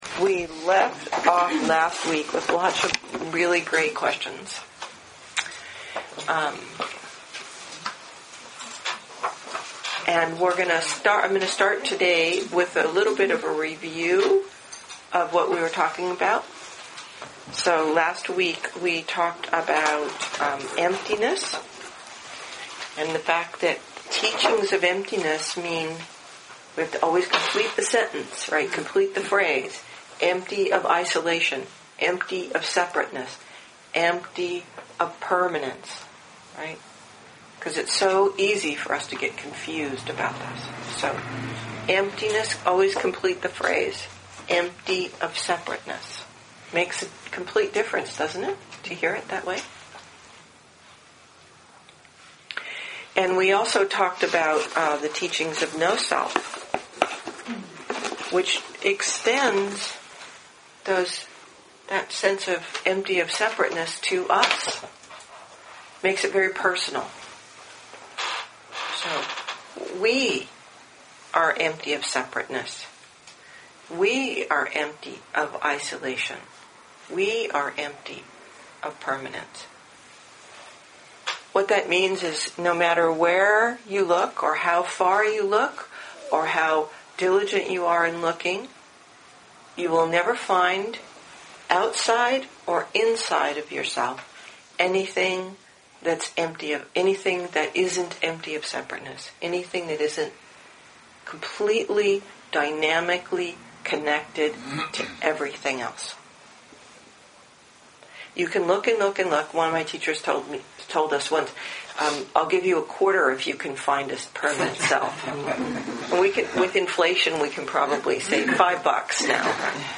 2015 in Dharma Talks